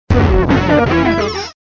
Fichier:Cri 0426 DP.ogg